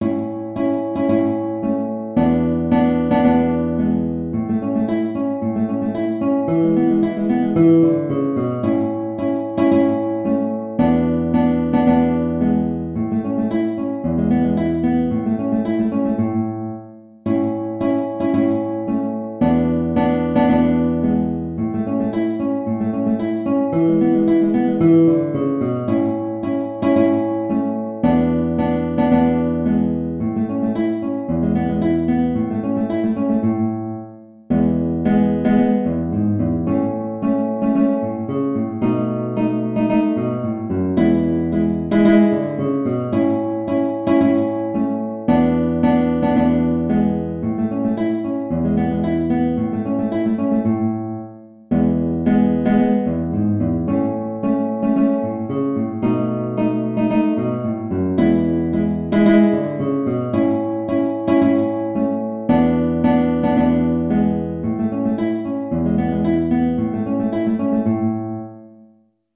A major (Sounding Pitch) (View more A major Music for Guitar )
Maestoso = 56
2/2 (View more 2/2 Music)
E3-E5
Guitar  (View more Easy Guitar Music)
Classical (View more Classical Guitar Music)
Carcassi_March_A_GTR.mp3